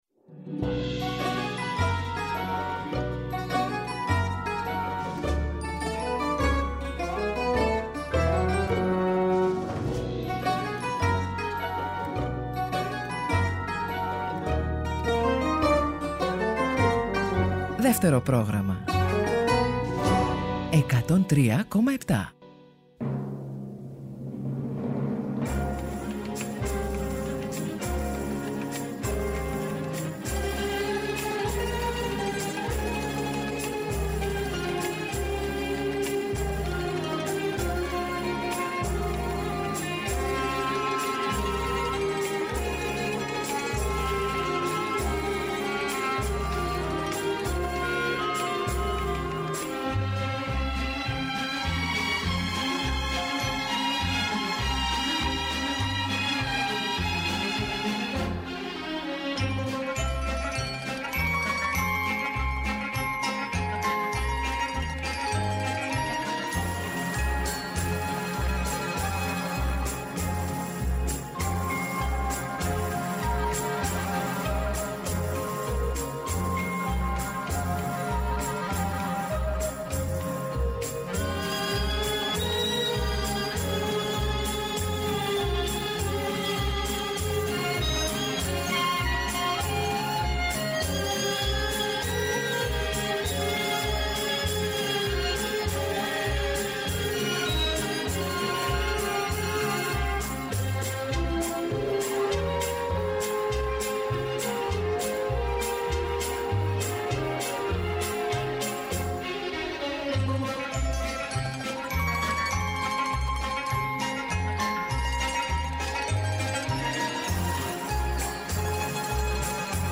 οργανικά και ορχηστρικά έργα